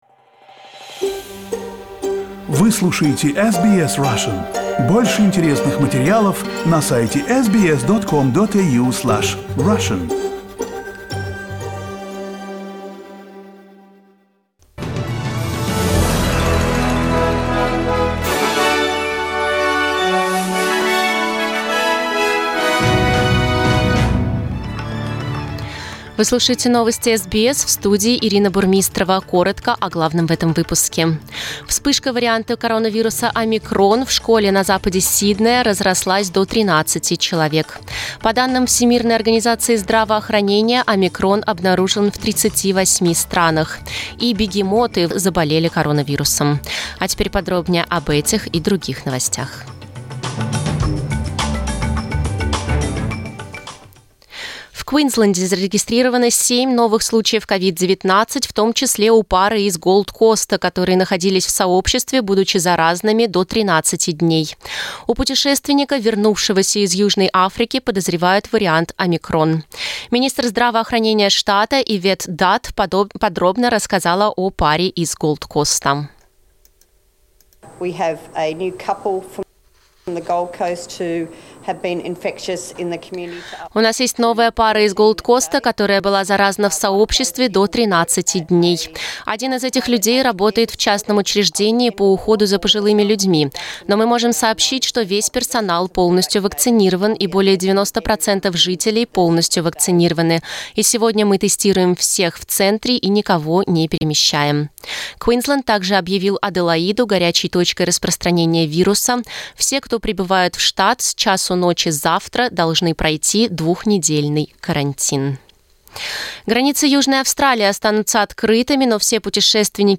Новости SBS на русском языке - 4.12